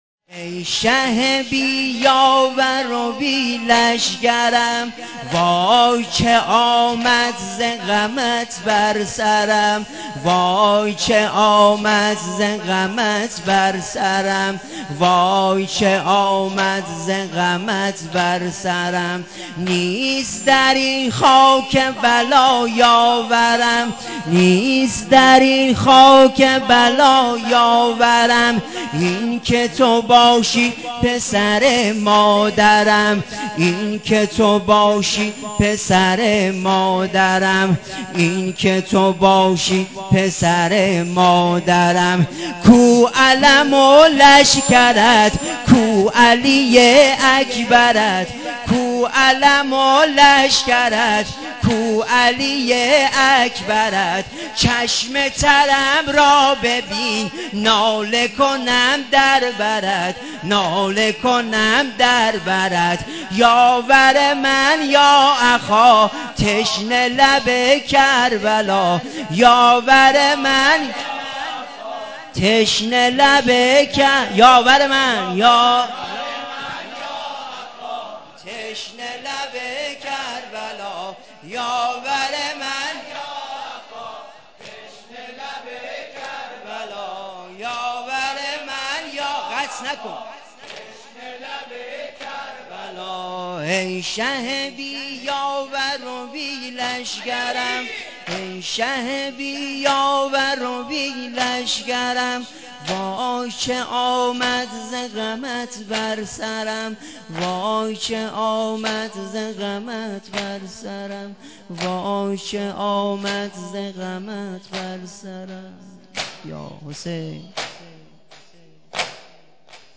نوحه سینه زنی شب عاشورا (تشنه لب کربلا)محرم ۹۶